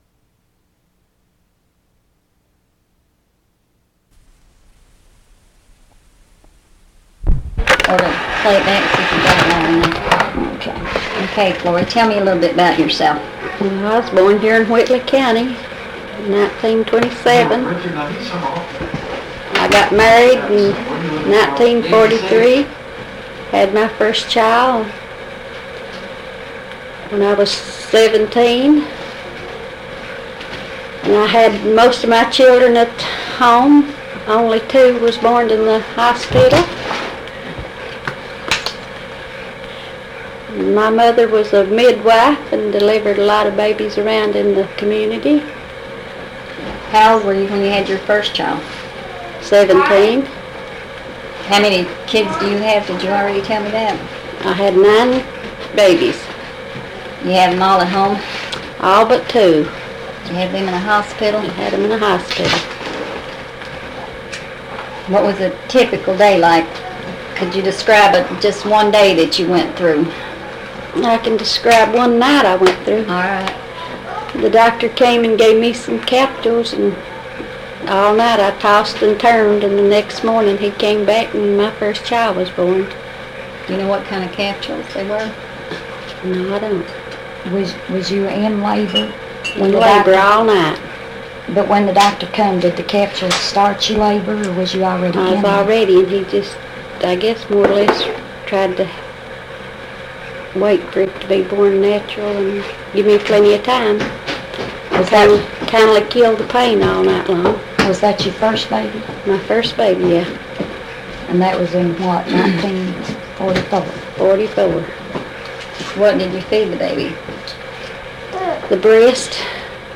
Oral History Interview with Unidentified Interviewee